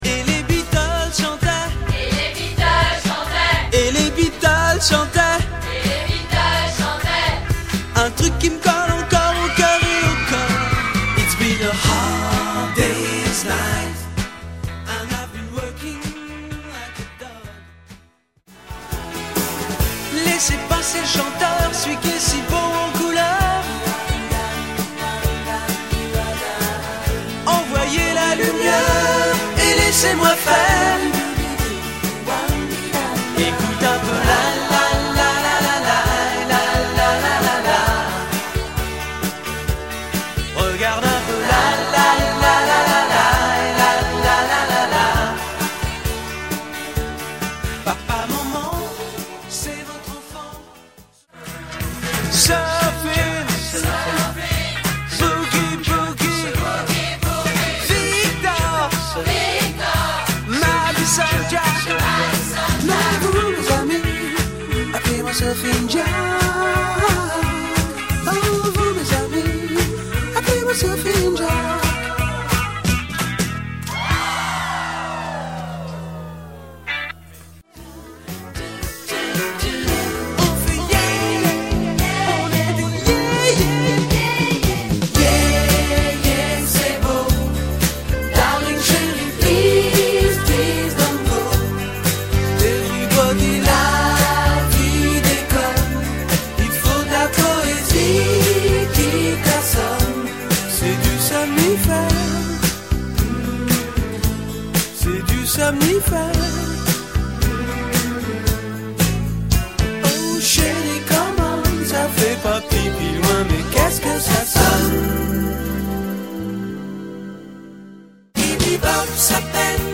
Choeurs
L’HARMONISATION DES CHŒURS